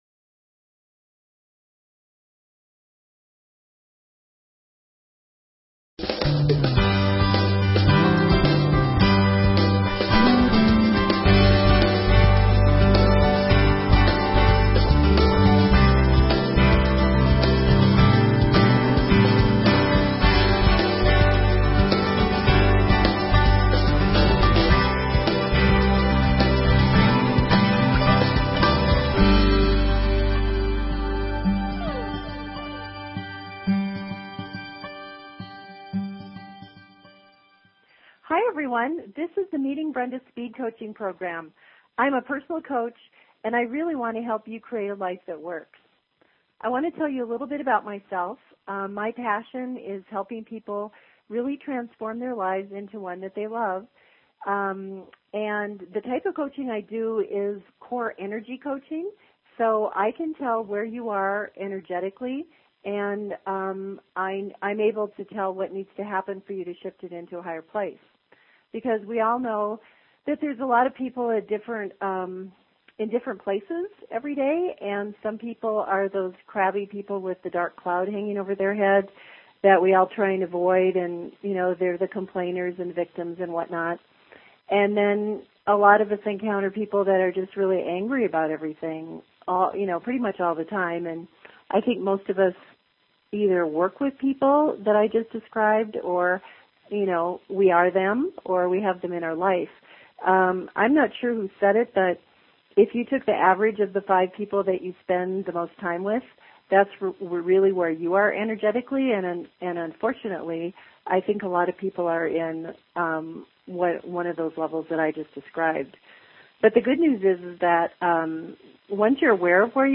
Talk Show Episode
Callers want ideas on how they can shift their energy into a better feeling state of mind.